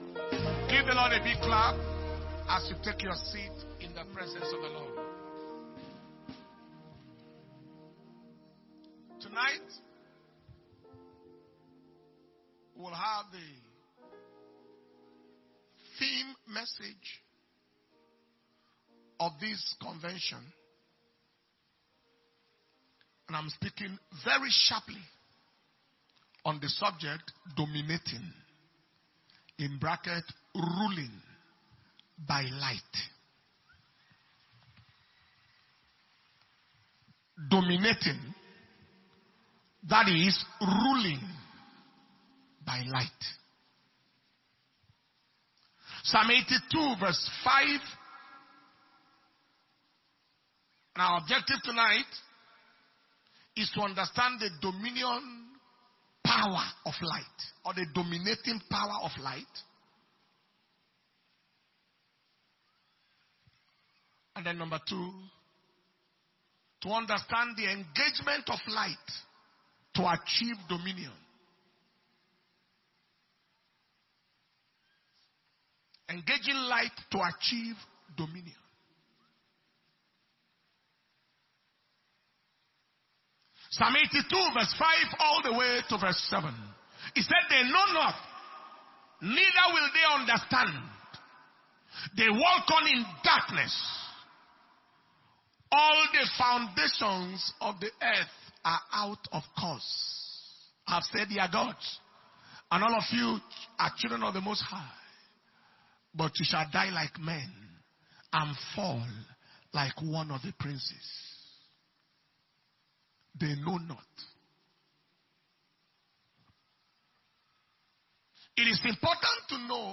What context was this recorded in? May 2023 Destiny Recovery Convention (Day 1 Evening Session)